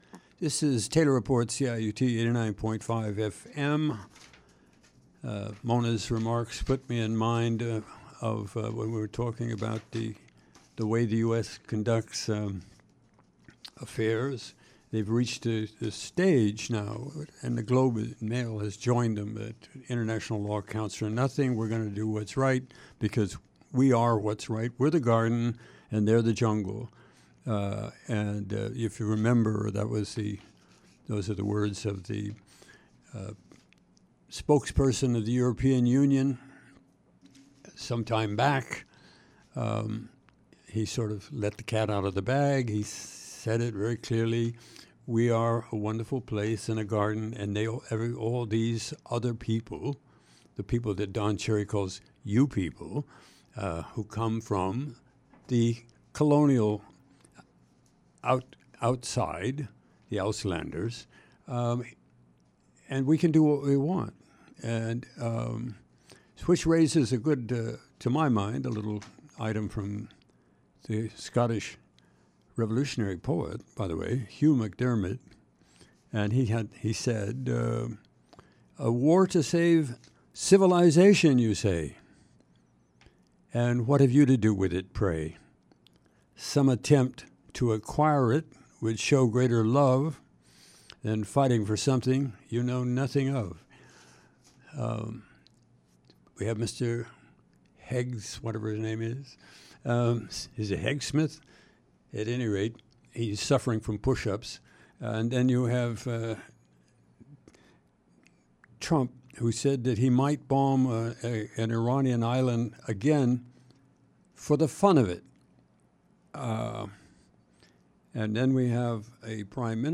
Taylor Report commentary